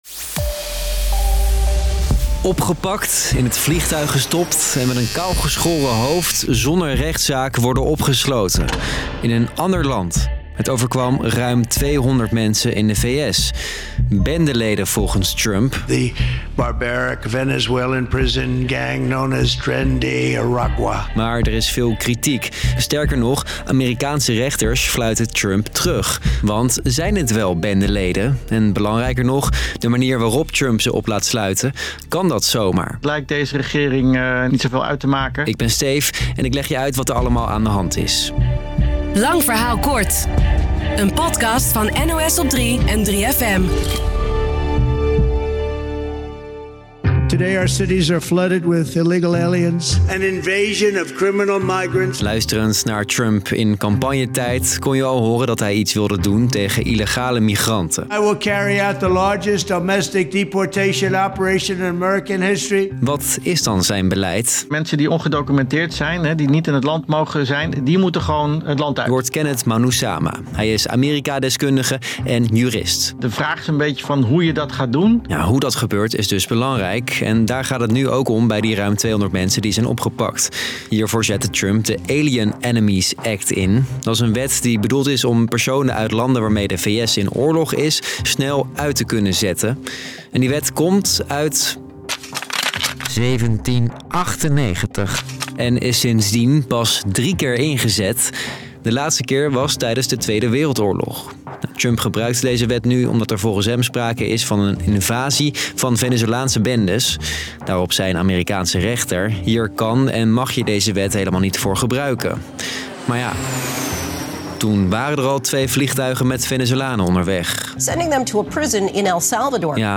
Een compilatie van een aantal (live)verslagen voor NOS Stories: